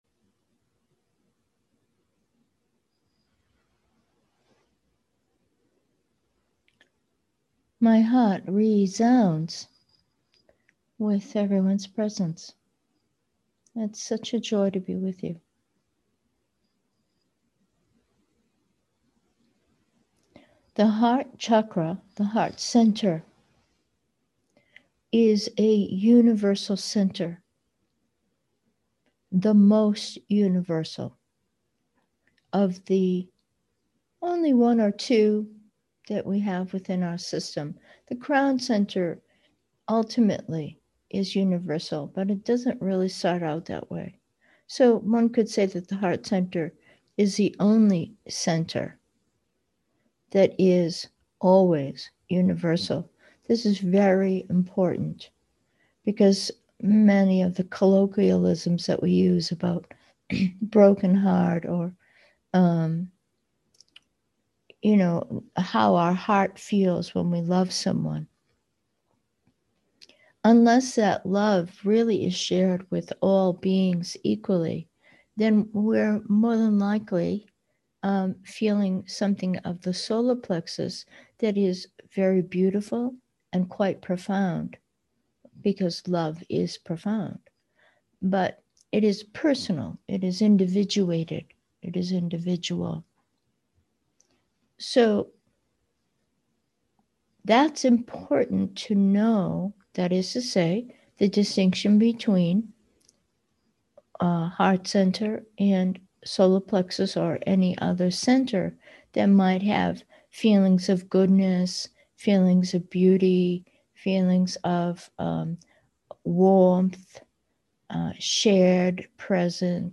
Meditation: heart 1, universal